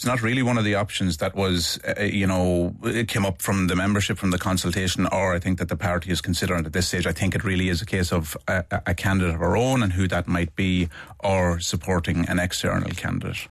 Sinn Fein TD Darren O’Rourke says branch meetings have taken place around the country, to discuss a bid for the Áras.